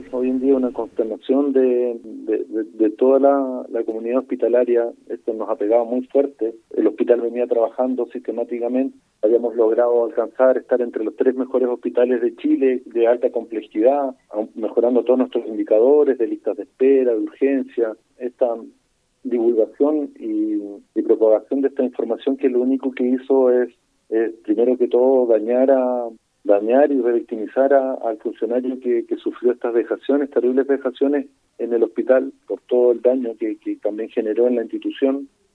En entrevista con Radio Bío Bío en la ciudad, la autoridad sanitaria fue consultada por el ánimo que hay entre los trabajadores tras lo expuesto, replicando que hay “una consternación de toda la comunidad hospitalaria, esto nos ha pegado muy fuerte“.